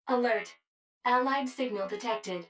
153_Allied_Signal_Detected.wav